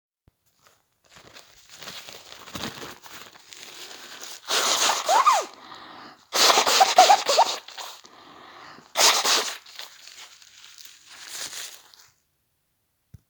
Ezeket a zajokat, zörejeket a fejlesztőházban vettem fel, amit mos közzé teszek.
4. Orrfújás
orrfújàs.mp3